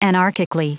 Prononciation du mot anarchically en anglais (fichier audio)
Prononciation du mot : anarchically